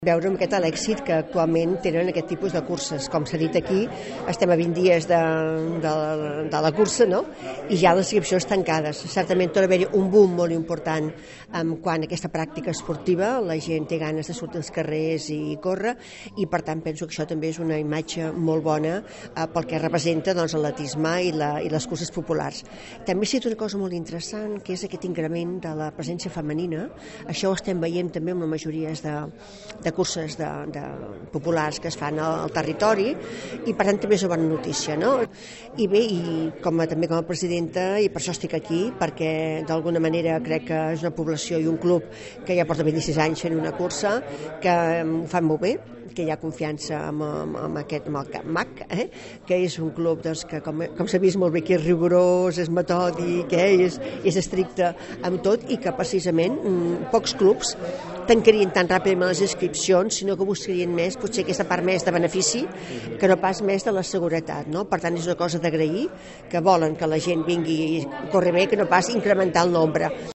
Presentada aquesta tarda a la Casa de la Vila la 26a edició de la Cursa de Martorell, una cita coorganitzada per l’Ajuntament i el Martorell Atlètic Club (MAC).